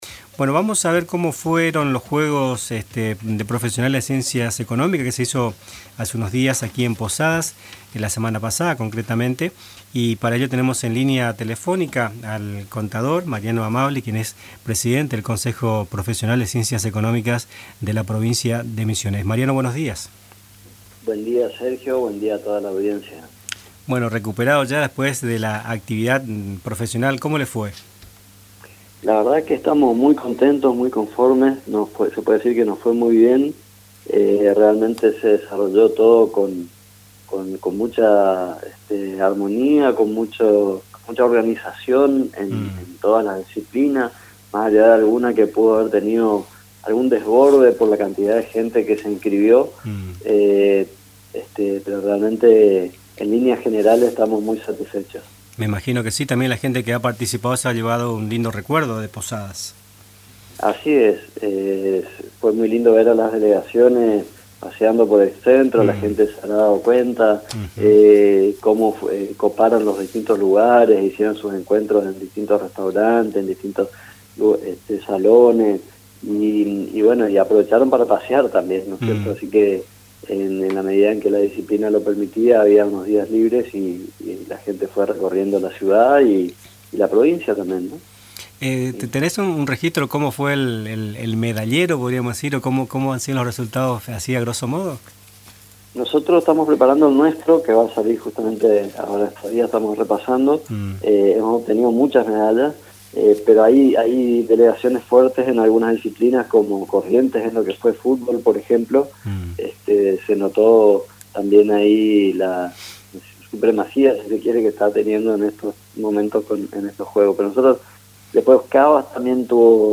puede escuchar completo en la entrevista